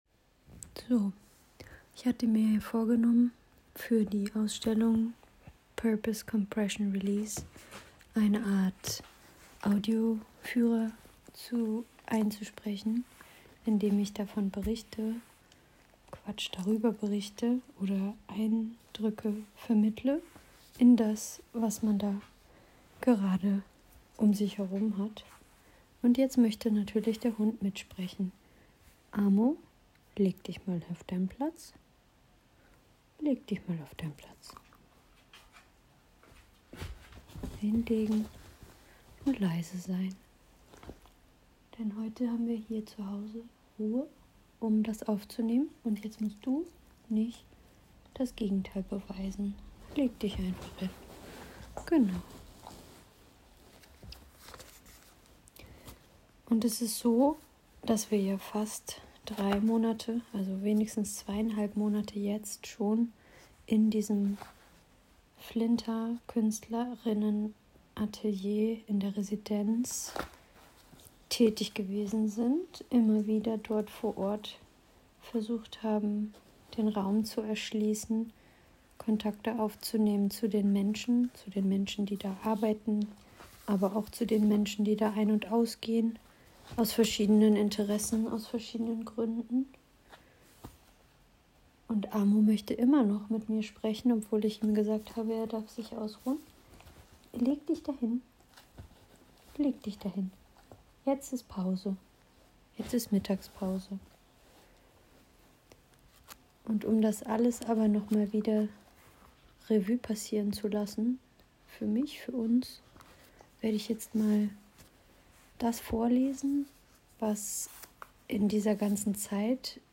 TRE (3) Kommentierte Lesung von rotsehen, blaumachen, und schwarzmalen, oder wenigstens undurchsichtig. Ausstellungskonzeption zur FLINTA* Residenz im aufhof Hannover, 2024